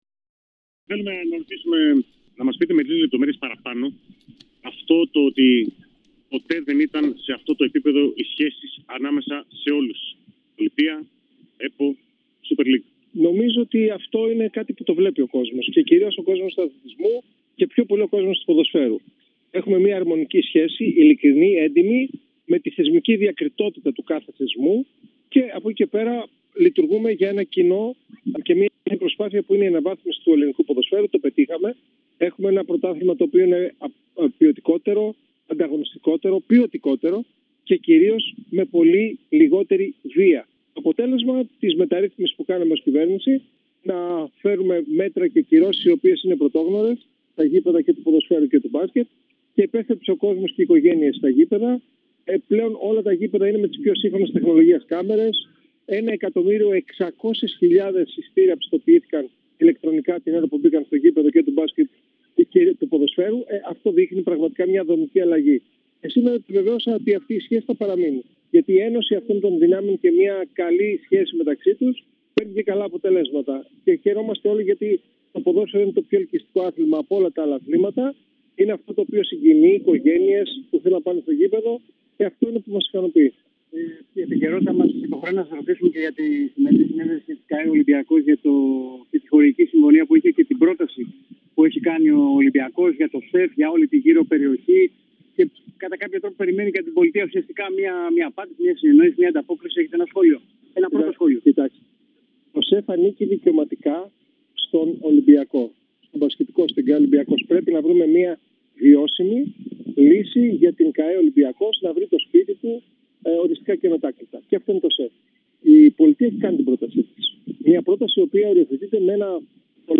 Ο αναπληρωτής Υπουργός Αθλητισμού μίλησε για το μείζον ζήτημα του ΣΕΦ, αλλά και το ελληνικό ποδόσφαιρο, στο περιθώριο της κλήρωσης της Super League.
Ακούστε όσα είπε στην ΕΡΑ ΣΠΟΡ ο Γιάννης Βρούτσης: